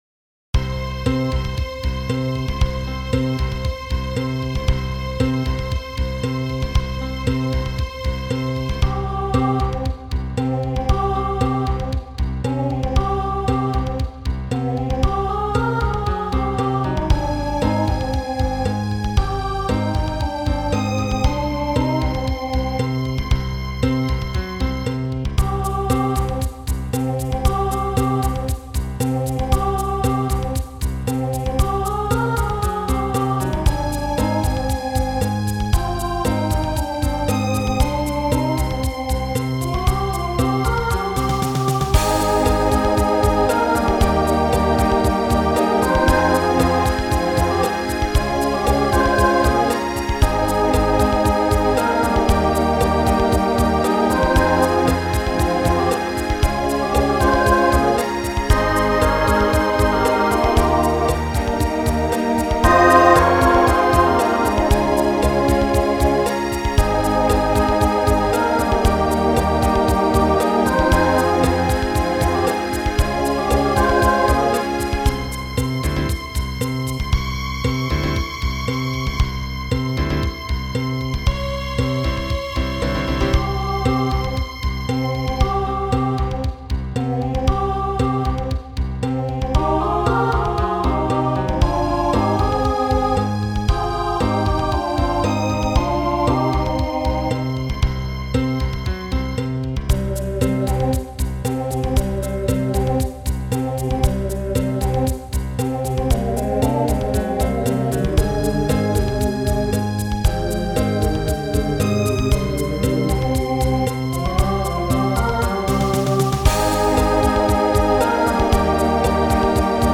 Voicing SATB Instrumental combo Genre Pop/Dance
Show Function Ballad